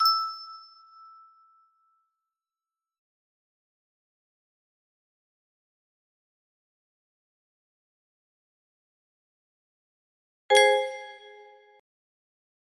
Empty music box melody